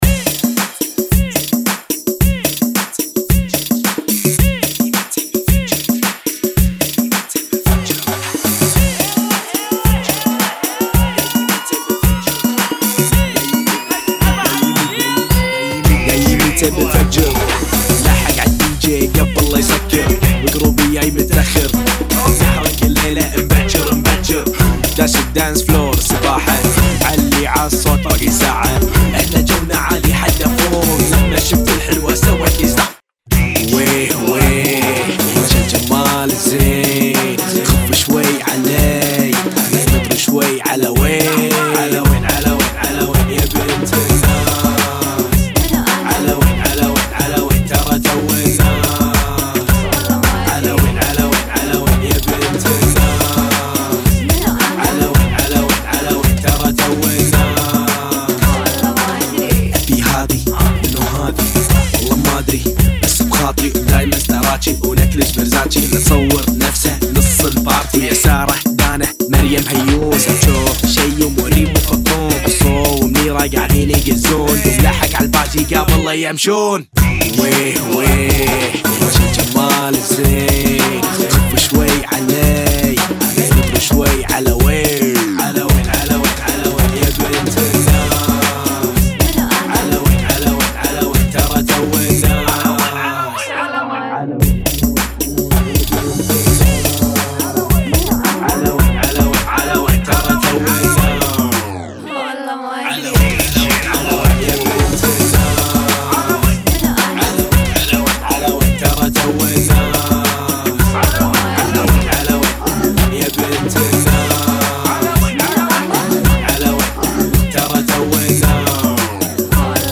فنكي ريمكس 2021